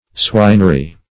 Swinery \Swin"er*y\ (sw[imac]n"[~e]r*[y^])